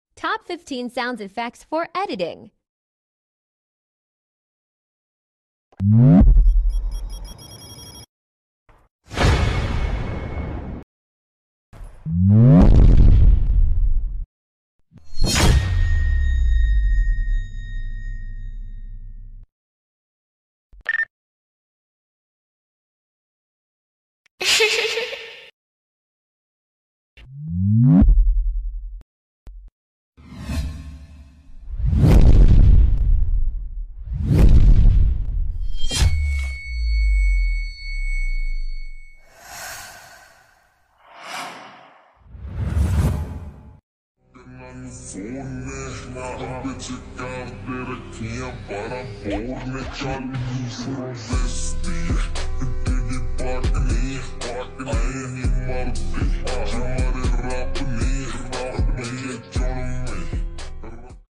Top15 sounds effects for editing😍❤ sound effects free download